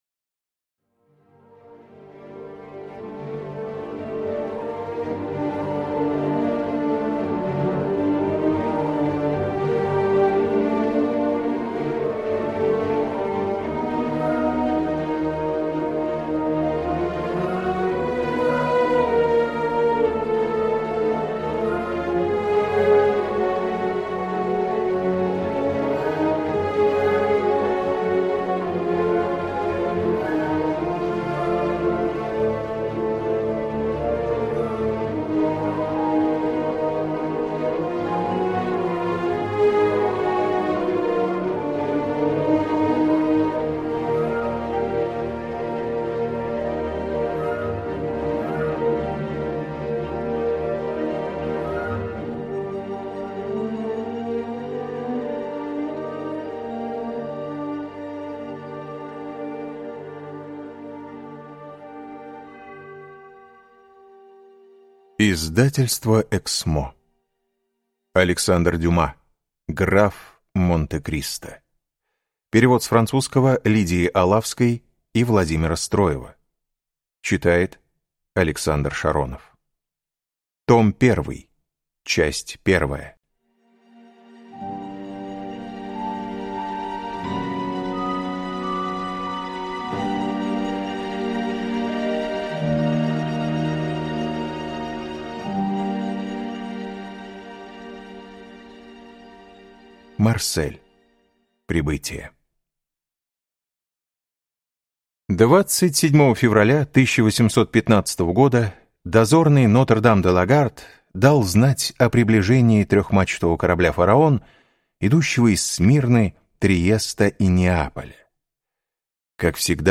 Аудиокнига Граф Монте-Кристо | Библиотека аудиокниг